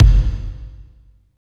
32.04 KICK.wav